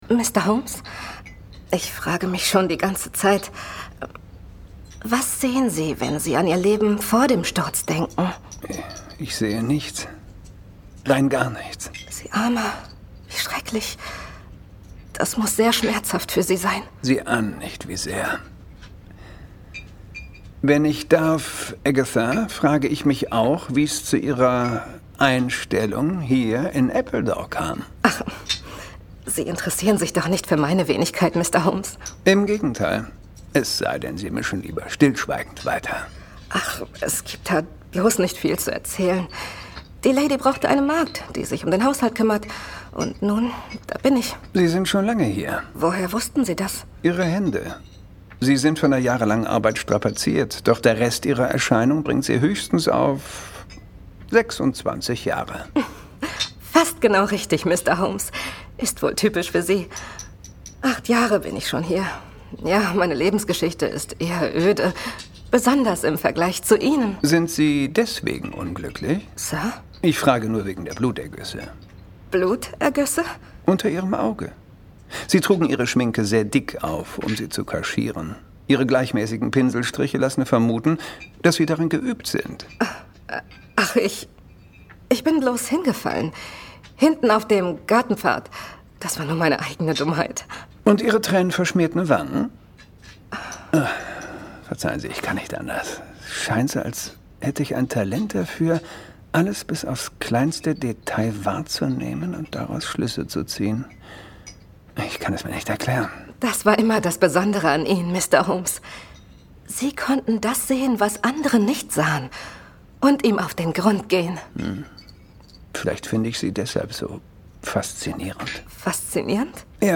Hörbuch Demo - SciFi Krimi